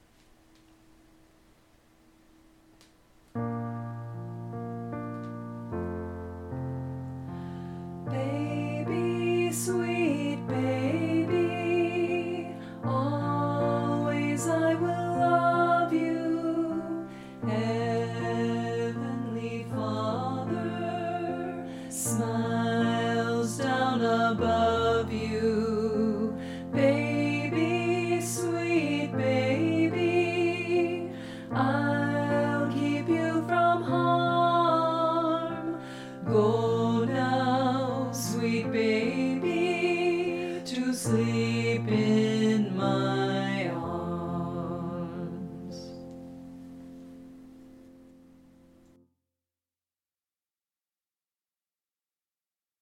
Vocal Solo
Medium Voice/Low Voice
Lullabies